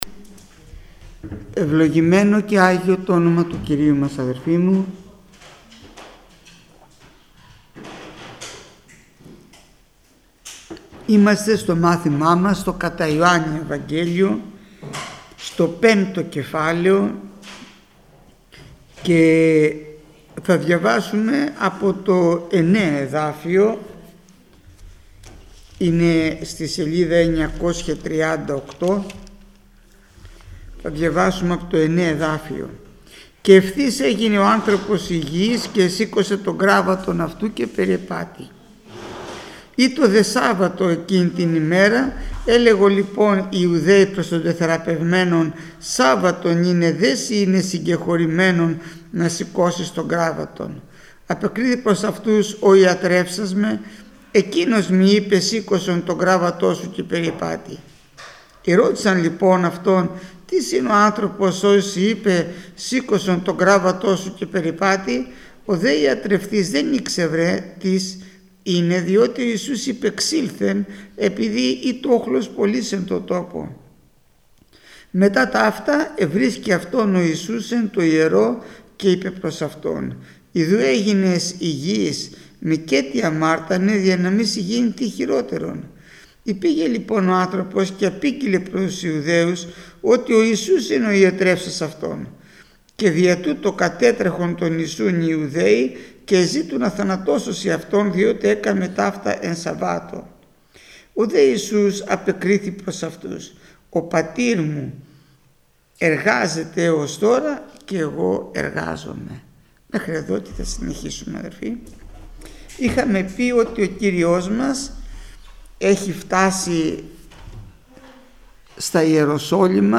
Μάθημα 431ο Γεννηθήτω το θέλημά σου